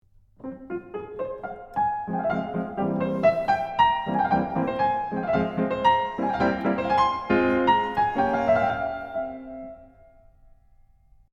Let’s take the example of accompaniment from the dictionary, and do to it what Beethoven makes with his left hand in the beginning of the sonata: